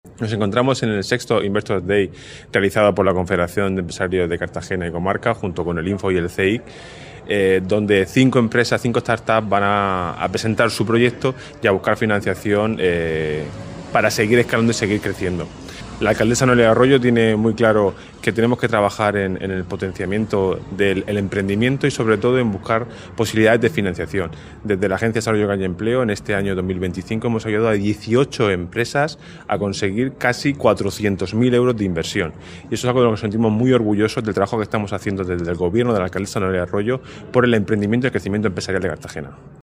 El encuentro, celebrado en el auditorio municipal El Batel, reunión a inversores con empresas emergentes para fomentar su crecimiento